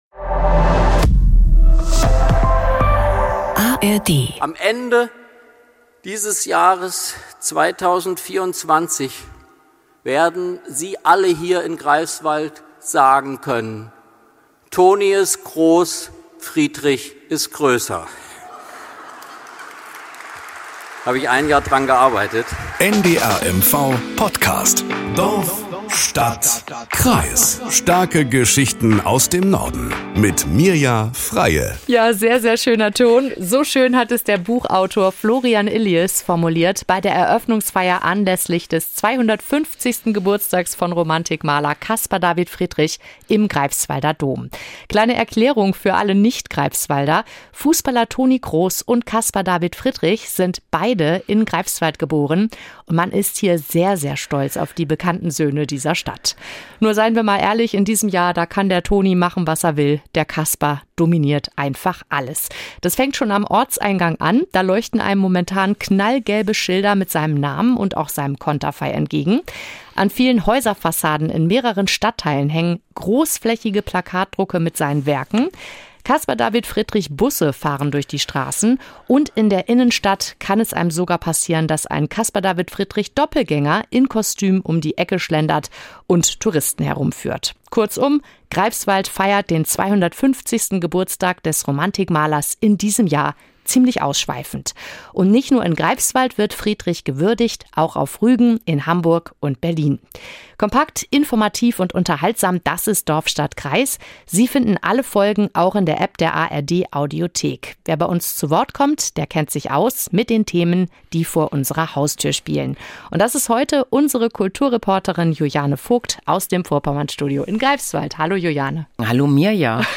Nachrichten aus Mecklenburg-Vorpommern - 04.06.2024